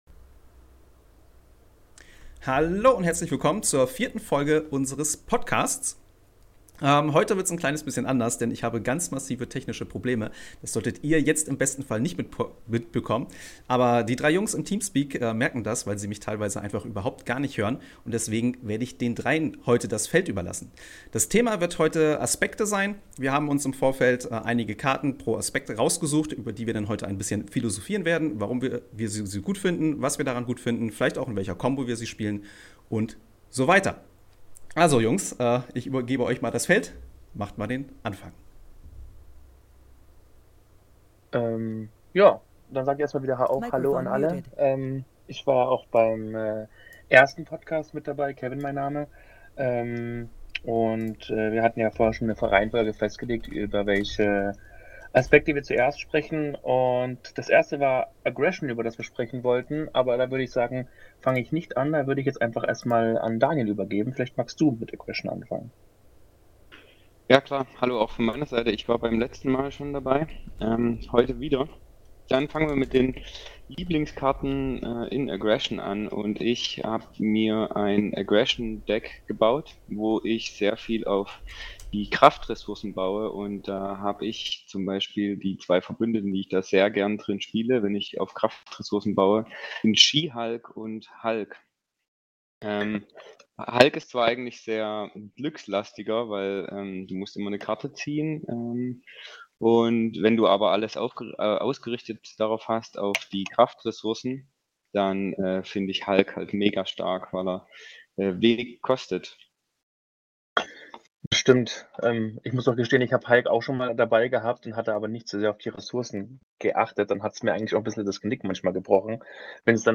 Daher lade ich jedes mal bis zu drei meiner Zuschauer ein um mich über verschiedene Themen zu unterhalten.